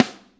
Snare 20.wav